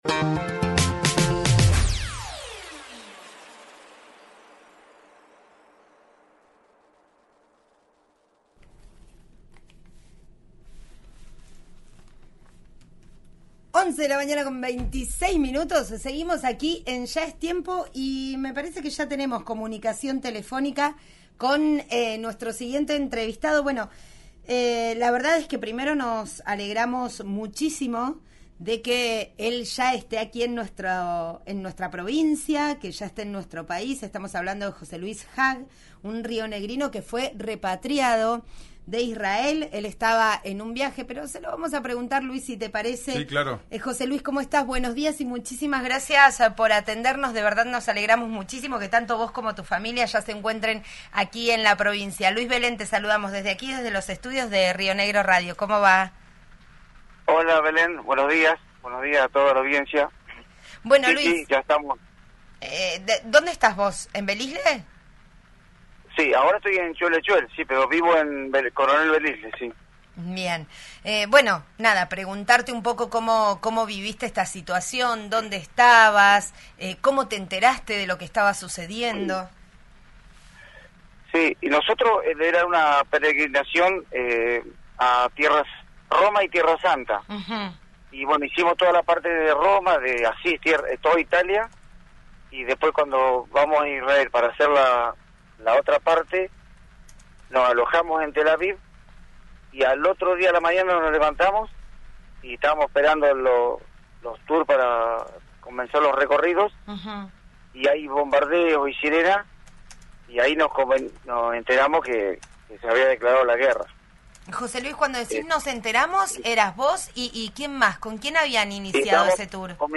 rionegrino-repatriado-de-israel.mp3